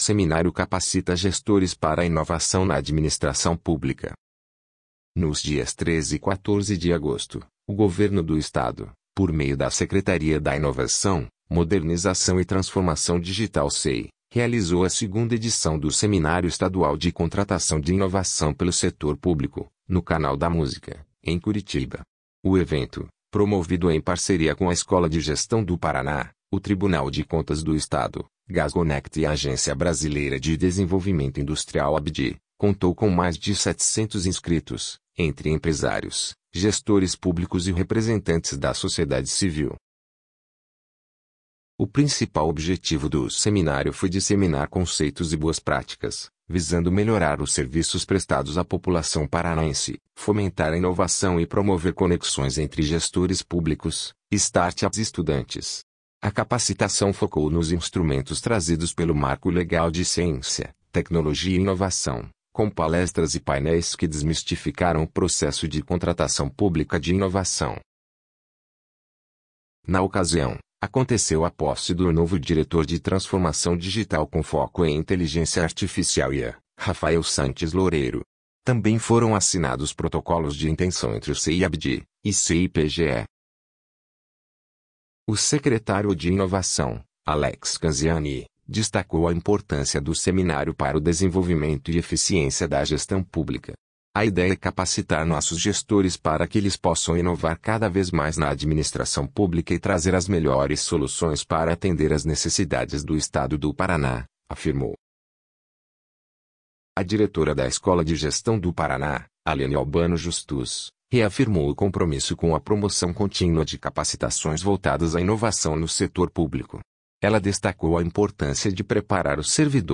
audionoticia_inovacao_na_adminsitracao_publica.mp3